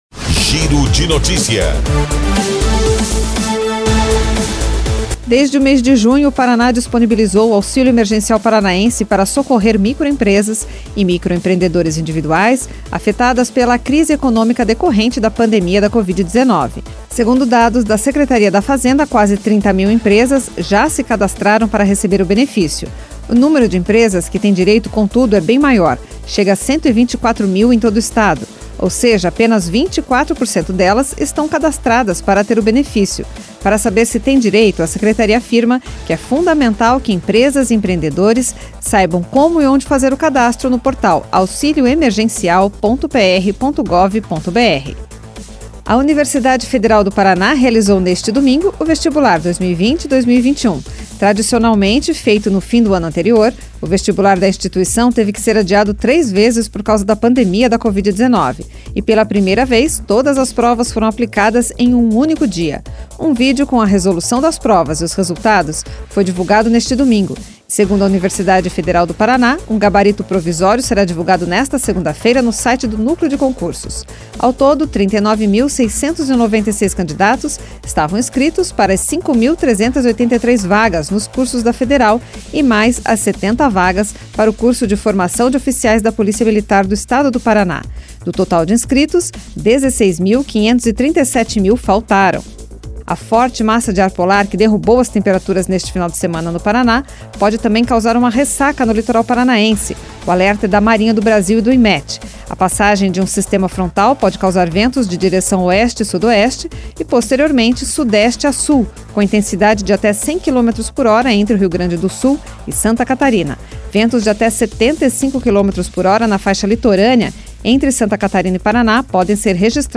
Giro de Notícias Manhã